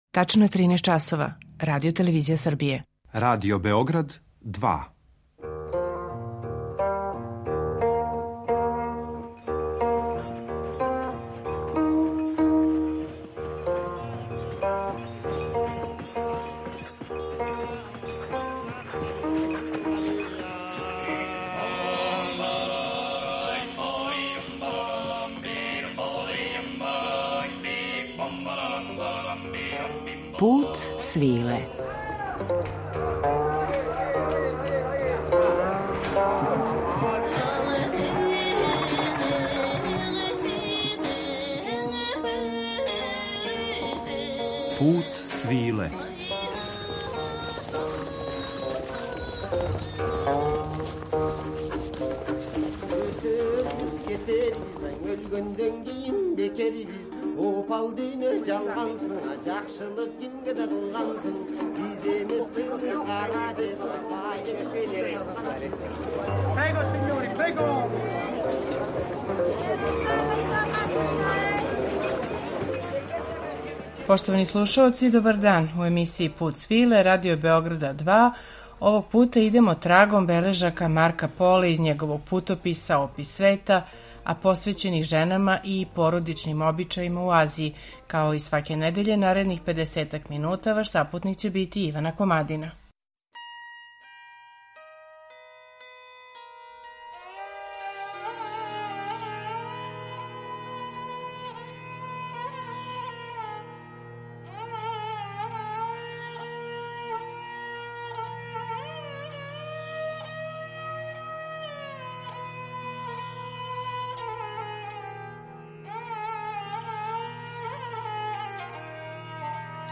уз актуелна остварења из жанра “World music” и раритетне записе традиционалне музике.